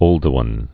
(ōldə-wən, ôl-)